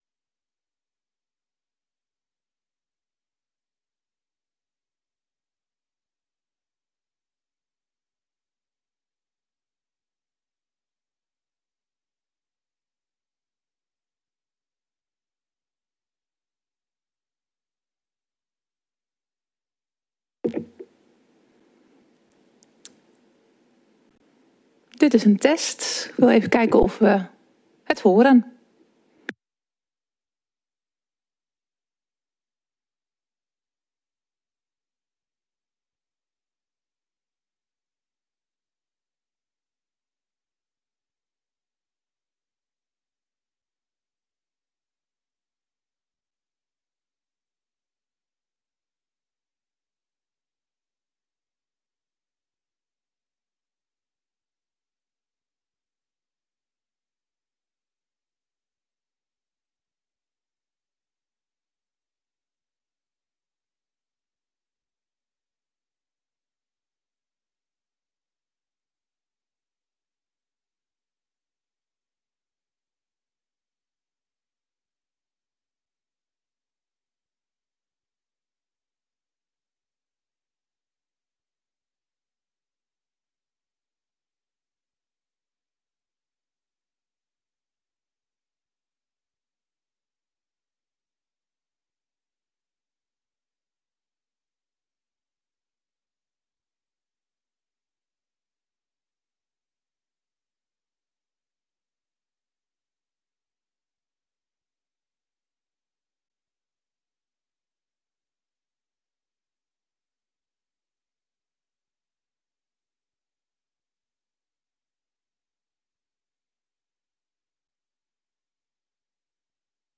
Beeldvormende vergadering 14 april 2022 19:30:00, Gemeente Dronten
Omdat er geen parallelle sessie is in de commissiekamer, wordt de vergadering niet voor een lange pauze tussen de onderwerpen geschorst, maar gaan we, na wisseling van de woordvoerders, direct door met het volgende onderwerp.